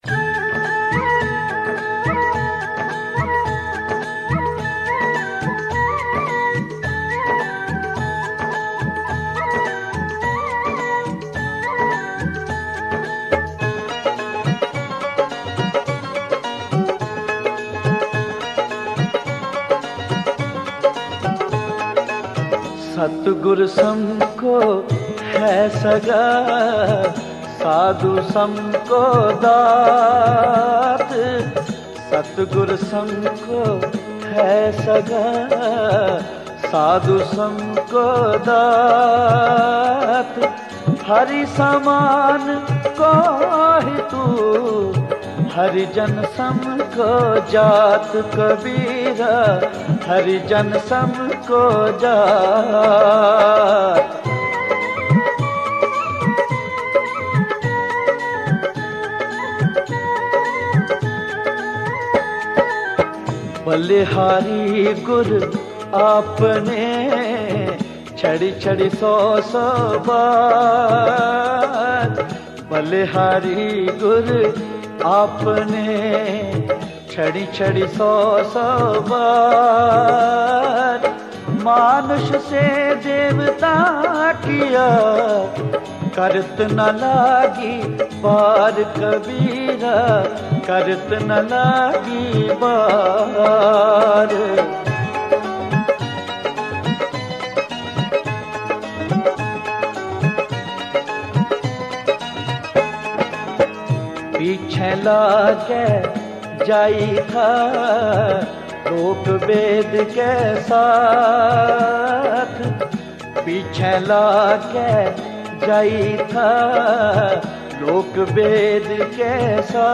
Genre: Shabad Gurbani Kirtan